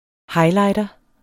Udtale [ ˈhɑjˌlɑjdʌ ]